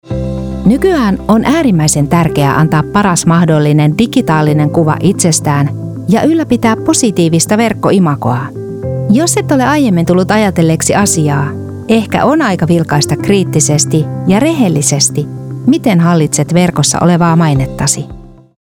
Corporate Videos
I record in a professional studio environment with professional recording equipment.
ConversationalTrustworthyEnergeticNeutralExpressive